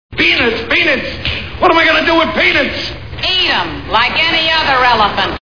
The Honeymooners TV Show Sound Bites